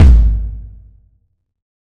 Kick
Original creative-commons licensed sounds for DJ's and music producers, recorded with high quality studio microphones.
Warm Sounding Kickdrum Sample A Key 569.wav
warm-sounding-kickdrum-sample-a-key-569-s5w.wav